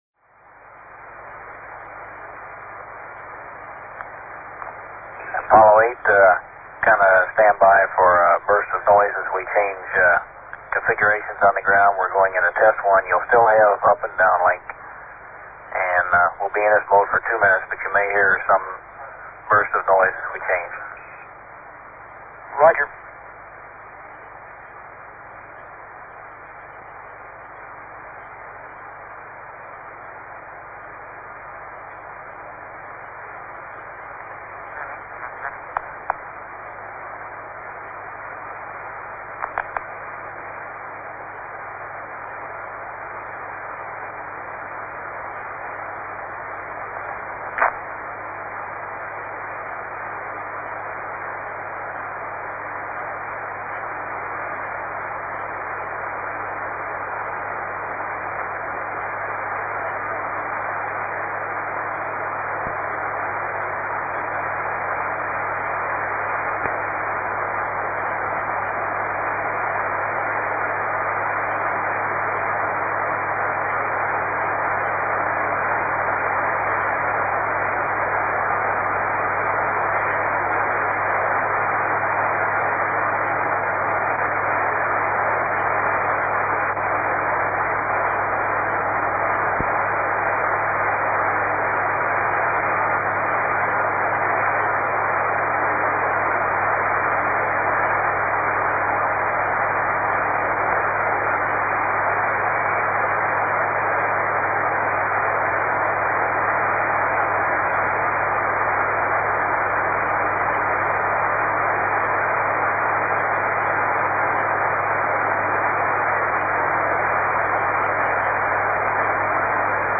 As recorded at Honeysuckle. 872kb mp3 runs for 4 minutes 37 seconds.
From there, he could monitor the PAO audio, as well as Net 1 (air/ground), Net 2 and other voice loops.